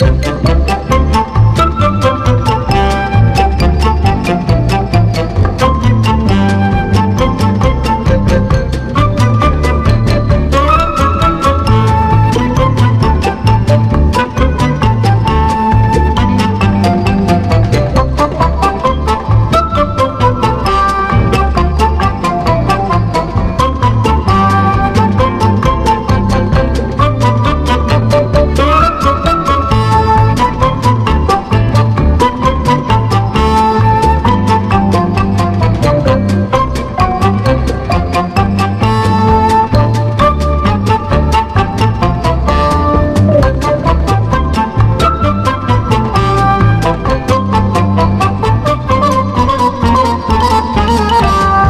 EASY LISTENING / EASY LISTENING / LATIN / LATIN FUNK
ブラックネス溢れる、驚きのラテン/ファンキー・アルバム！